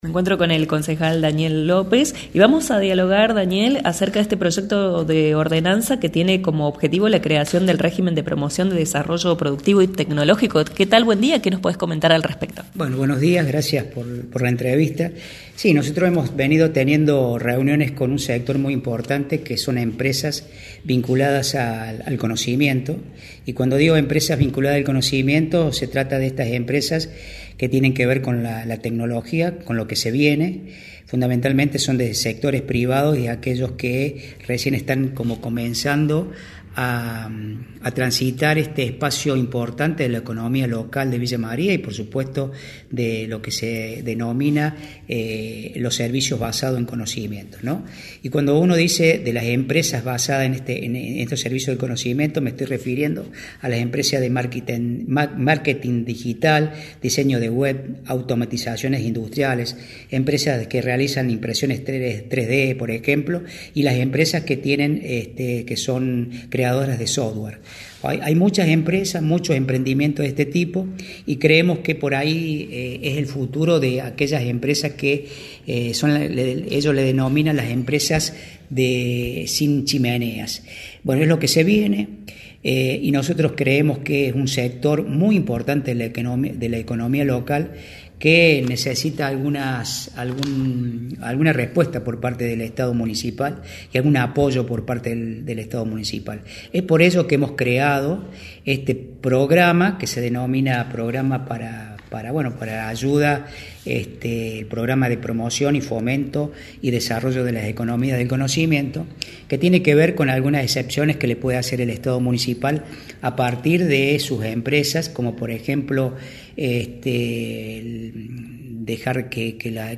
Dialogamos con el concejal Daniel López, para indagar en el proyecto por el cual quieren crear un Régimen de Promoción del Desarrollo Productivo y Tecnológico.
Concejal-Daniel-Lopez.mp3